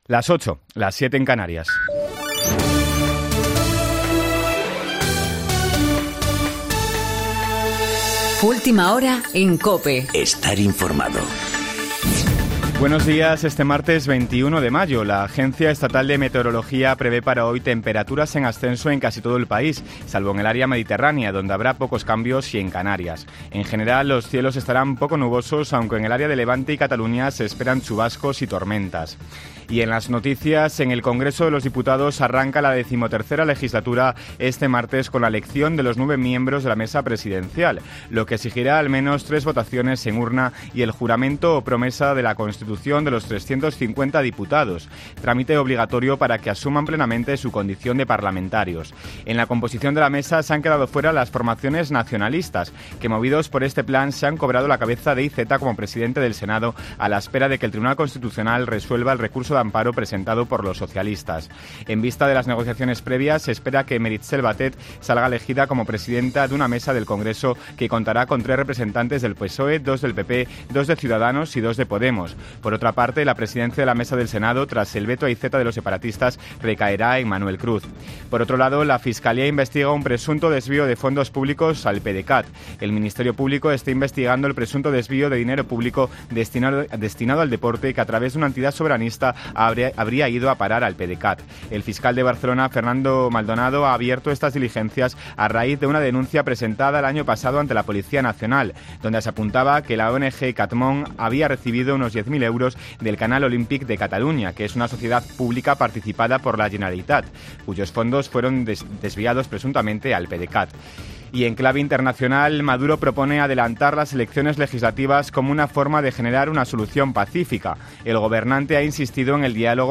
Boletín de noticias COPE del 22 de mayo de 2019 a las 08.00 horas